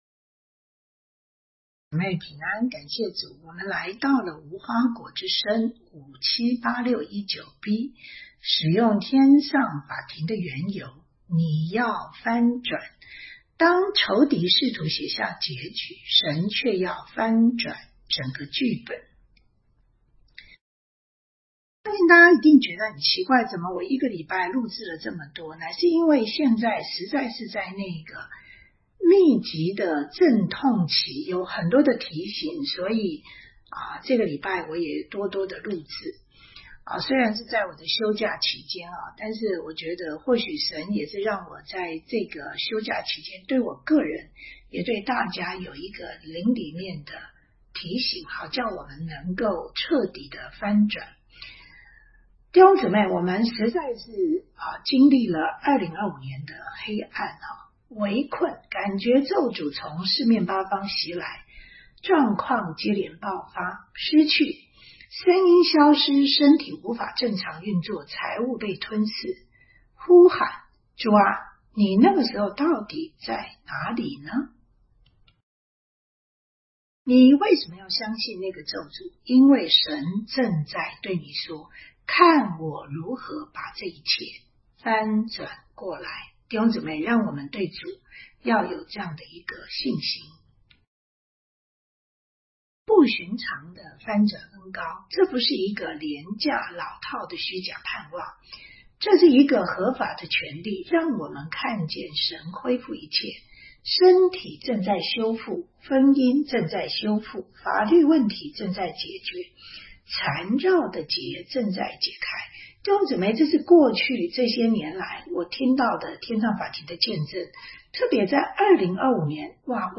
主题教导 每周妥拉